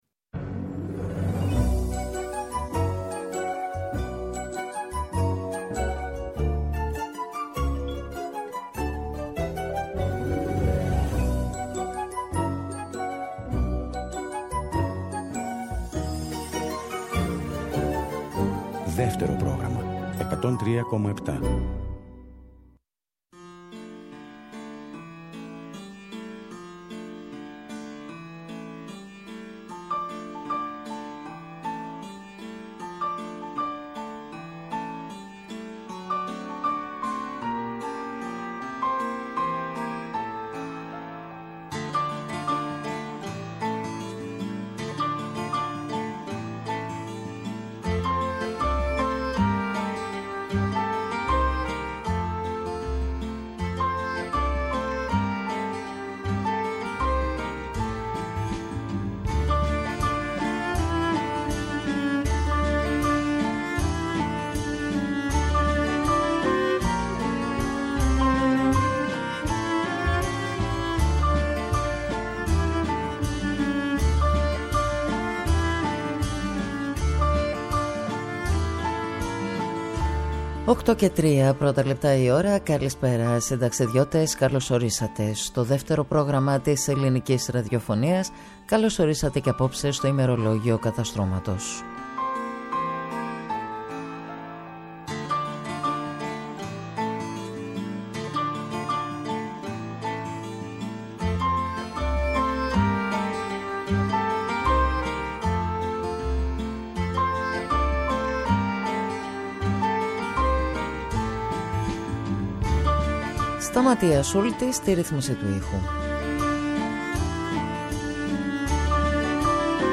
Συνταξιδιώτες οι ακροατές, ούριος άνεμος η μουσική και τα τραγούδια.